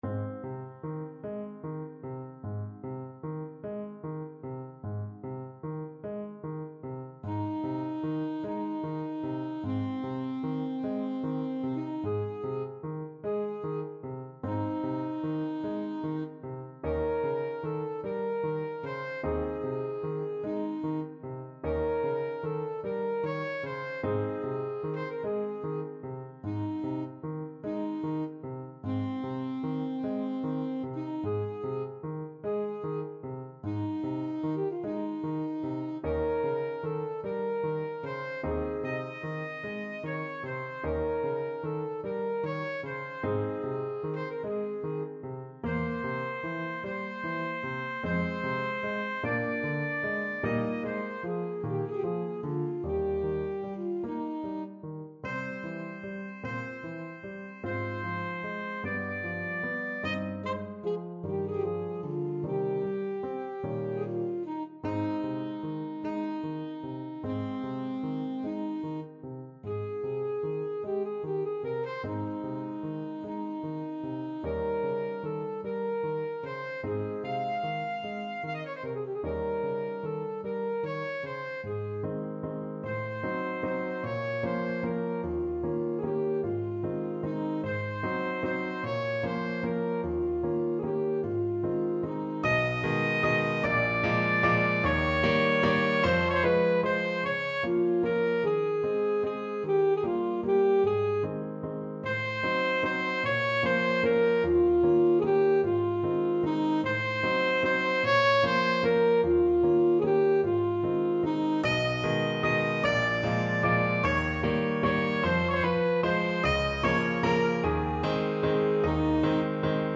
Alto Saxophone
Largo
4/4 (View more 4/4 Music)
Classical (View more Classical Saxophone Music)